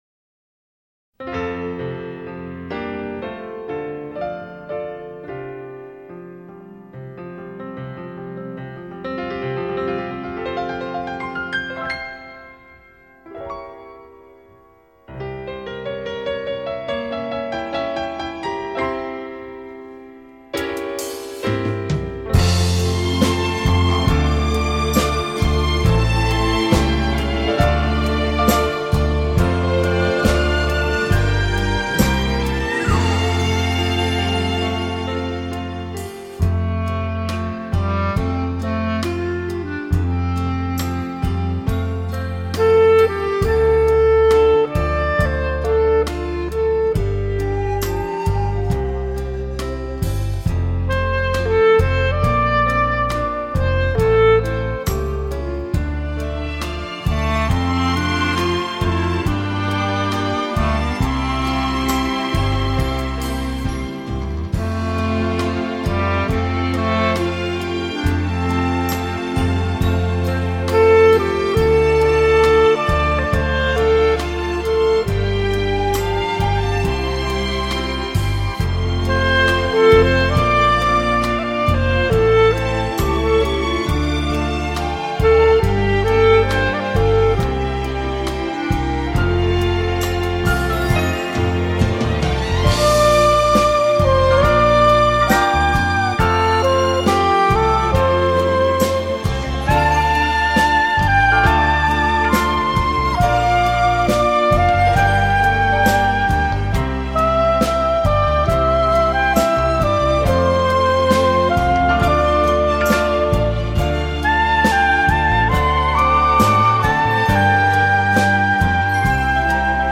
日本东京KING RECORDSTUDIO录音
碟中收录以演出多首耳熟能详的中国现代名歌创作曲，流露出浓郁的东方风情。
曲调悠扬动听、流畅深远，音色通透鲜明、浓郁至美，层次绝佳、传真度至高，非常的抒情。